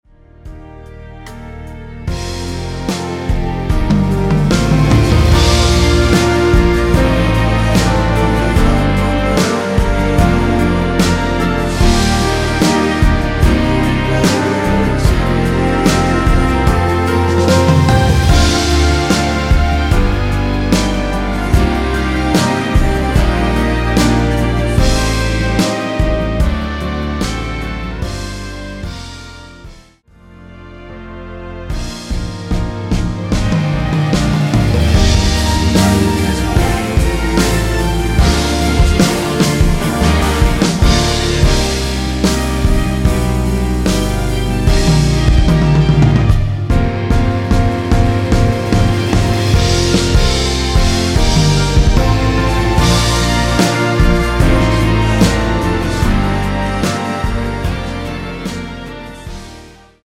원키에서(-2)내린 코러스 포함된 MR입니다.(미리듣기 확인)
Bb
앞부분30초, 뒷부분30초씩 편집해서 올려 드리고 있습니다.
중간에 음이 끈어지고 다시 나오는 이유는